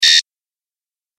دانلود صدای زنگ 17 از ساعد نیوز با لینک مستقیم و کیفیت بالا
جلوه های صوتی